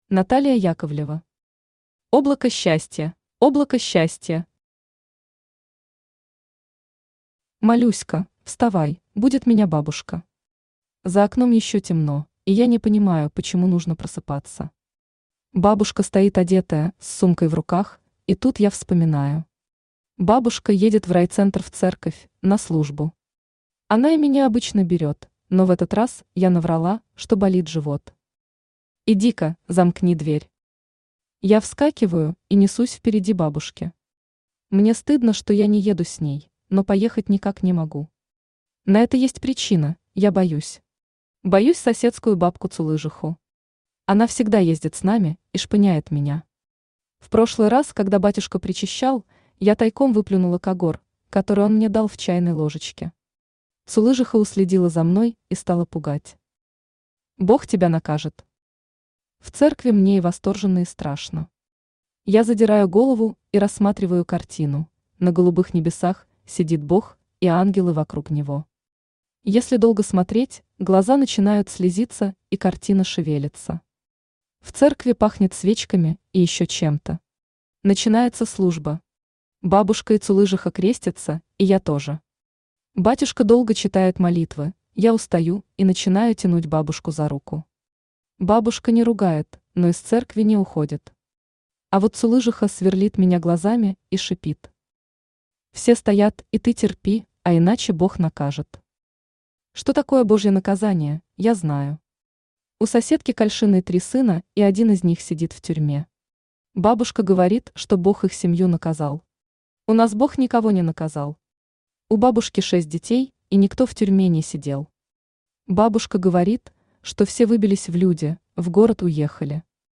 Аудиокнига Облако-счастье | Библиотека аудиокниг
Aудиокнига Облако-счастье Автор Наталия Яковлева Читает аудиокнигу Авточтец ЛитРес.